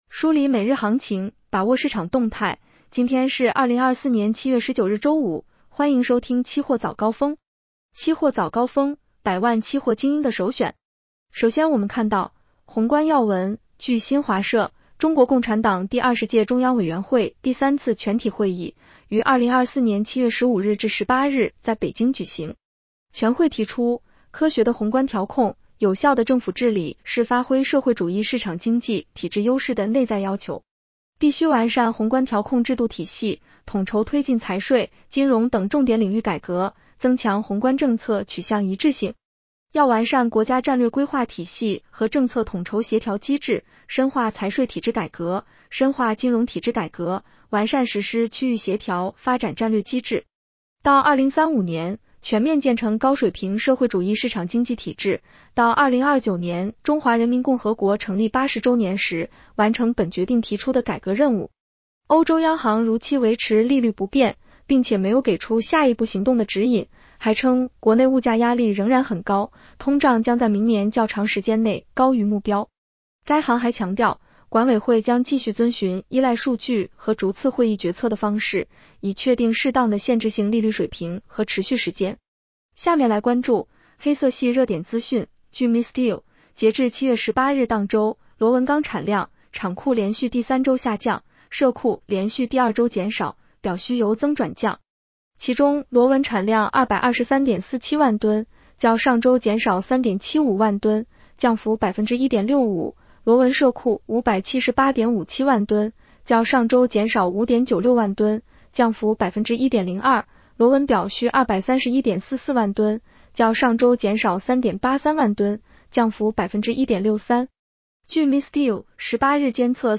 期货早高峰-音频版 女声普通话版 下载mp3 宏观要闻 1.